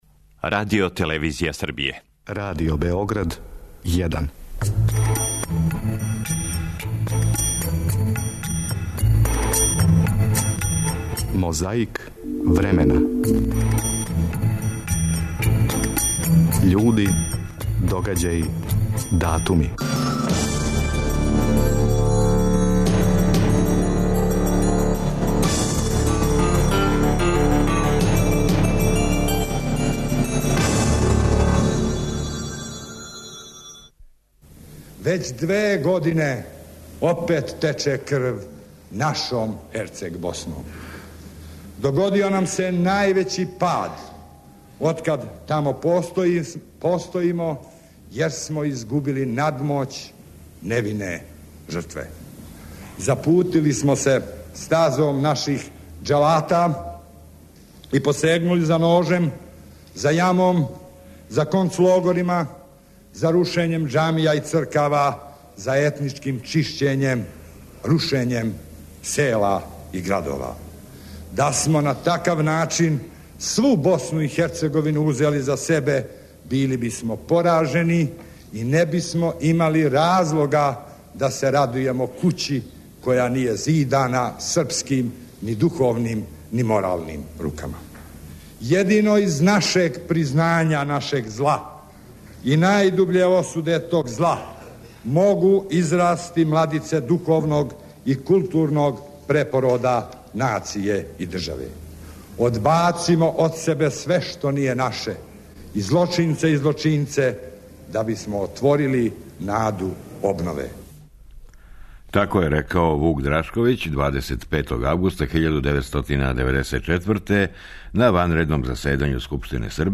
Подсетићемо се и шта је све речено на ванредном заседању Скупштине Србије 25. августа 1994. године, поводом предлога мировног плана Контакт групе за Босну и Херцеговину.